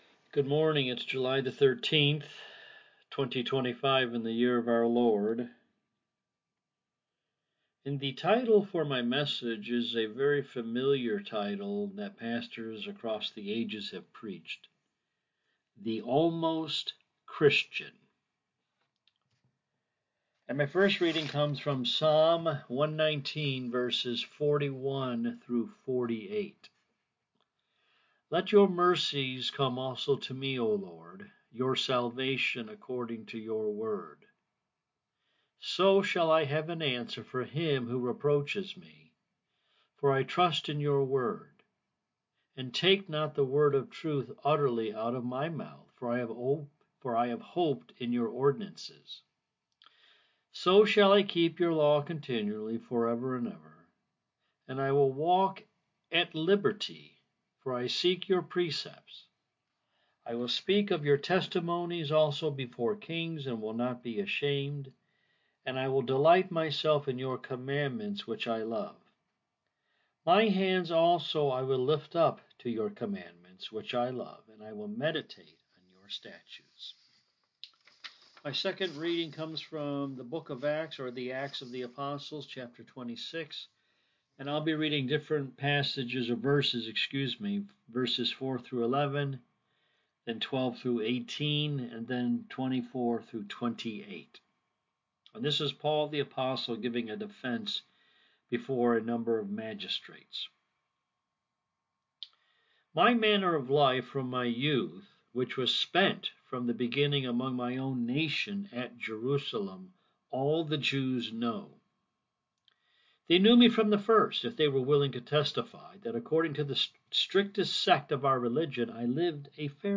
This is the second sermon in our series from Almost to Altogether by John Wesley. Paul as we read excerpts from Act 26 gave a thoughtful but passioned defense of his Christian faith before a mixed audience of Jew and Gentiles- royalty and common folk alike.